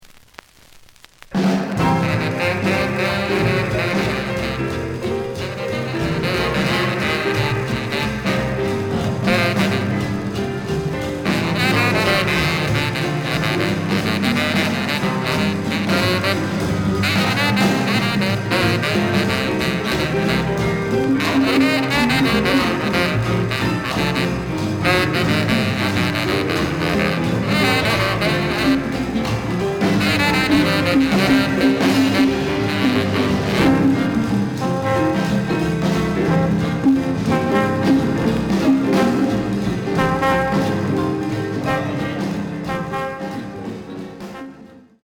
The audio sample is recorded from the actual item.
●Genre: Rock / Pop
Edge warp.